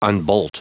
Prononciation du mot unbolt en anglais (fichier audio)
Prononciation du mot : unbolt